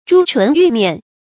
朱唇玉面 注音： ㄓㄨ ㄔㄨㄣˊ ㄧㄩˋ ㄇㄧㄢˋ 讀音讀法： 意思解釋： 指美女。